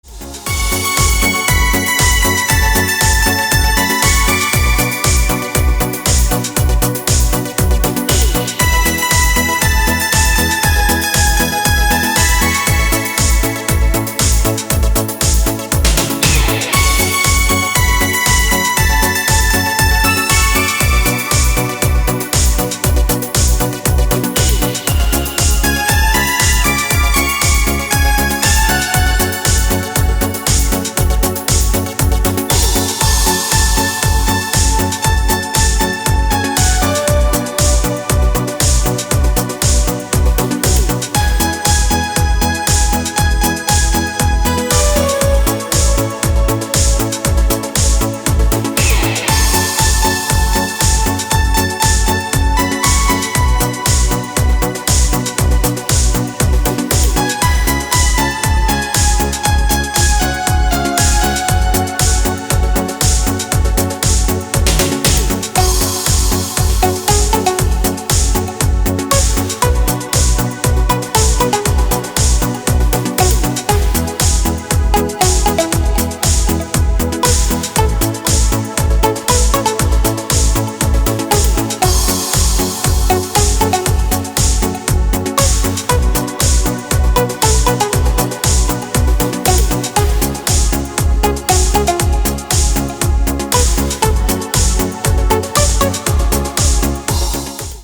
• Песня: Рингтон, нарезка
Мелодия 80-х для мобильного